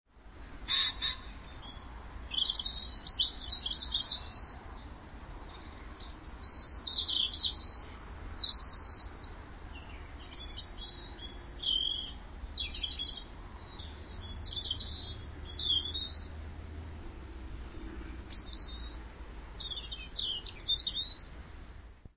Down into some woods, for a little
Listen to that and see whether you can identify the birds above the traffic noise.